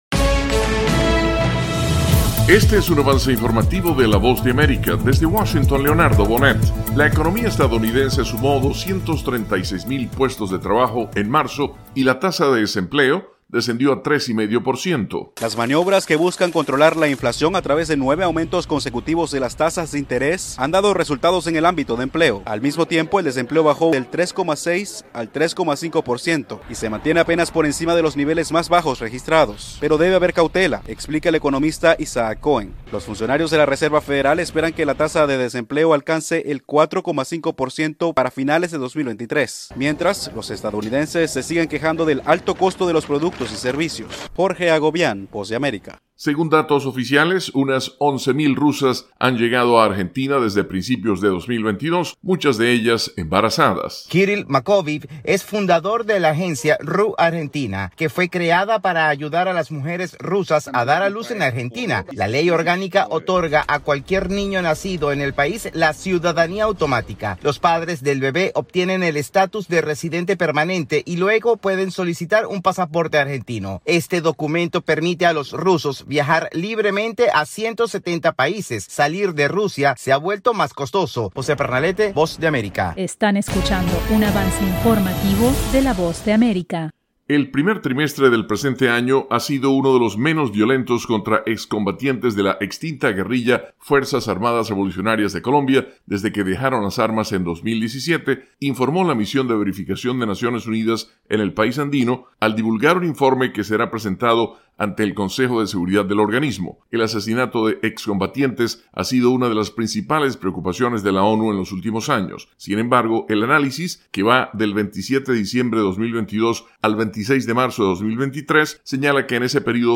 Avance Informativo 3:00 PM